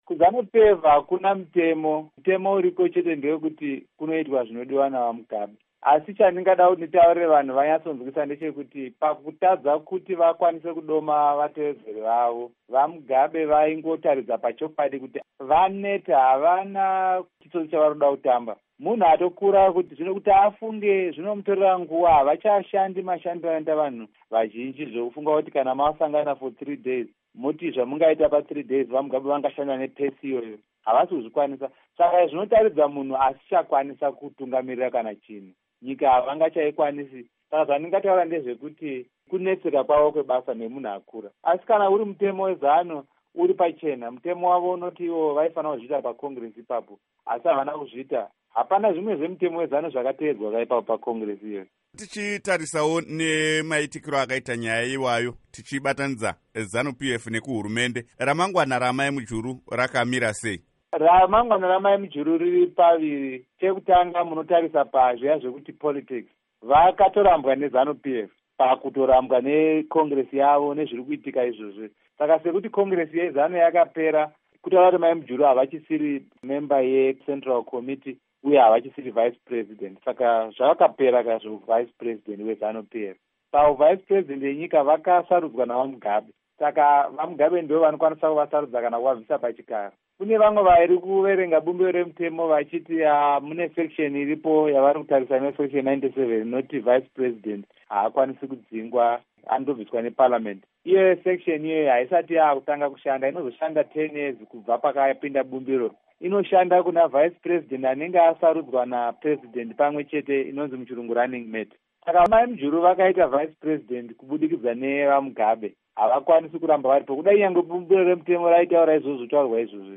Hurukuro naVaLovemore Madhuku